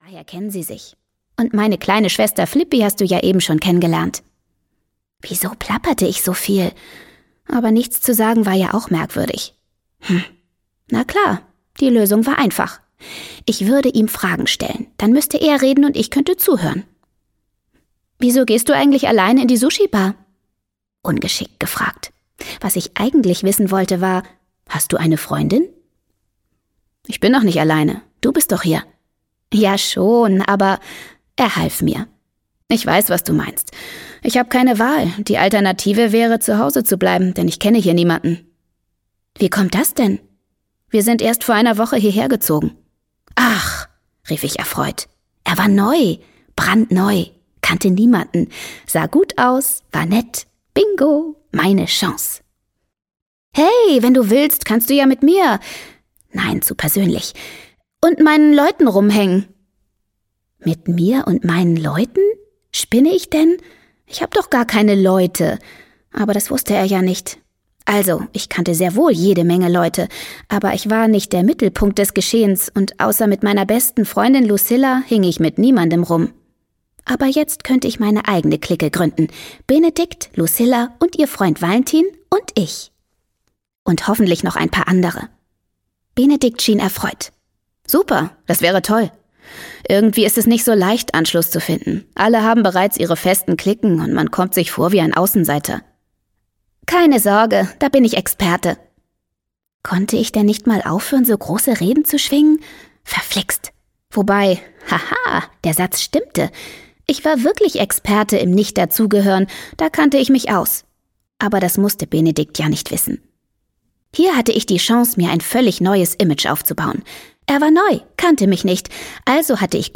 Freche Mädchen: Chaos & flambierte Herzen - Hortense Ullrich - Hörbuch